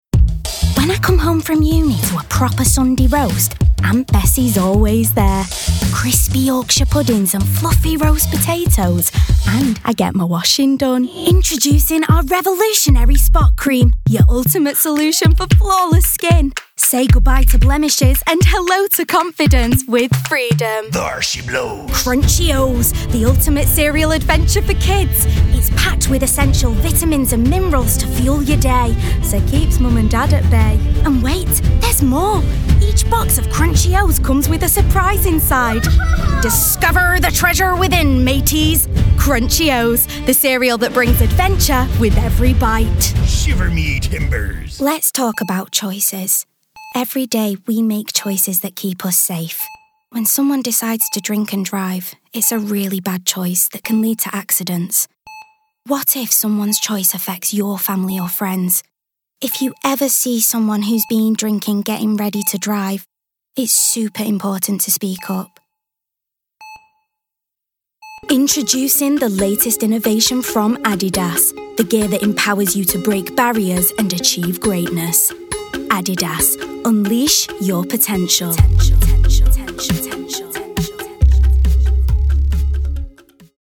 Commercial Showreel
Female
Manchester
Bright
Friendly
Youthful
Upbeat